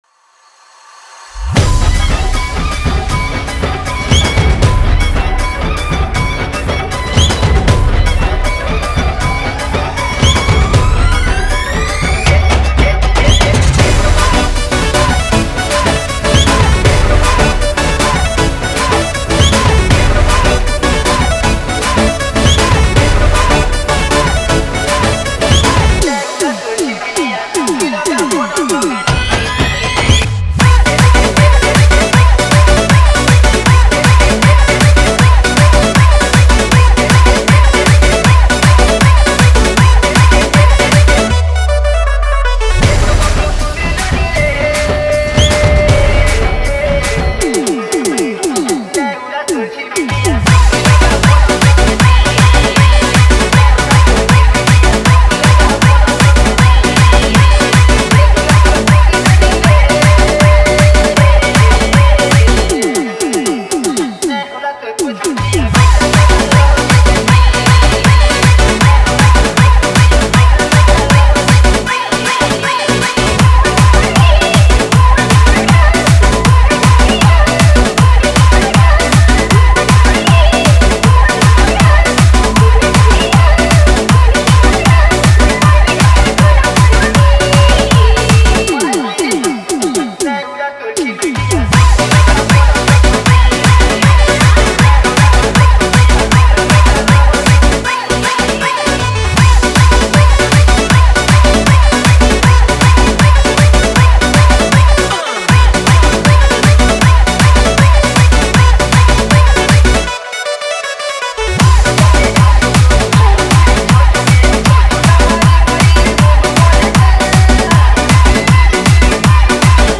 Category:  New Sambalpuri Dj Song 2020